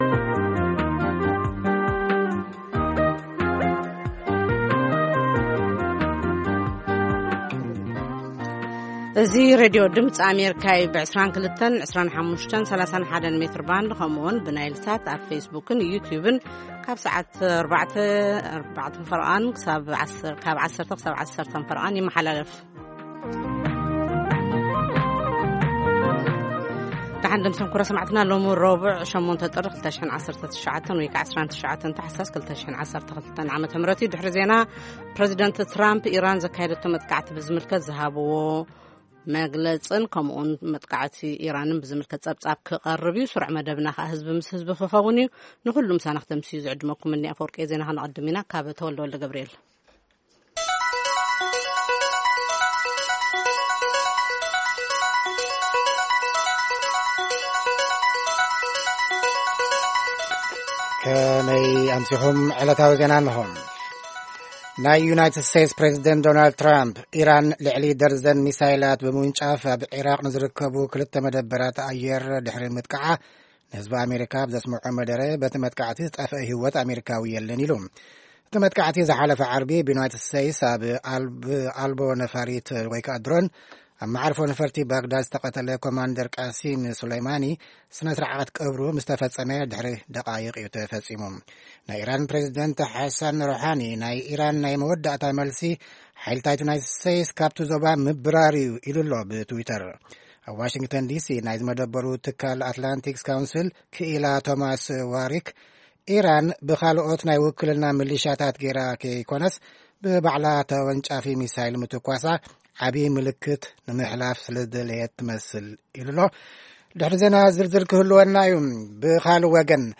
ፈነወ ትግርኛ ብናይ`ዚ መዓልቲ ዓበይቲ ዜና ይጅምር ። ካብ ኤርትራን ኢትዮጵያን ዝረኽቦም ቃለ-መጠይቓትን ሰሙናዊ መደባትን ድማ የስዕብ ። ሰሙናዊ መደባት ረቡዕ፡ ህዝቢ ምስ ህዝቢ